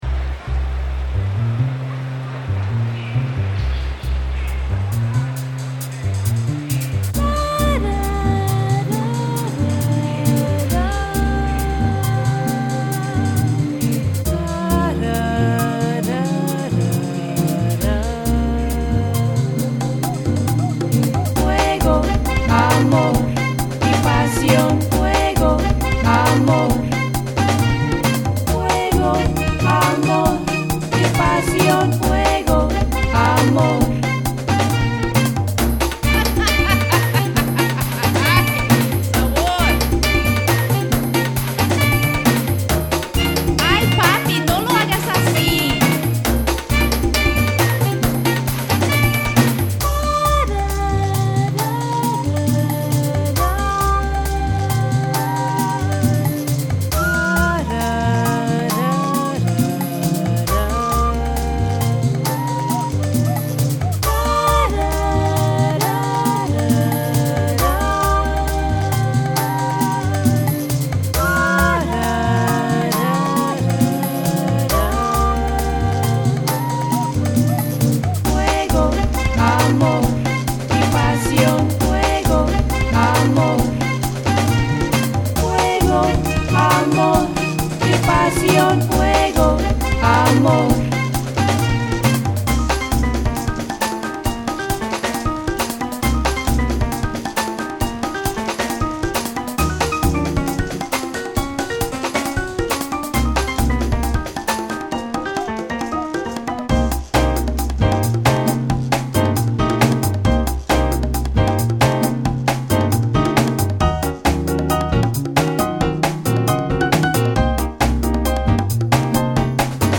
・Latin：135BPM
Latin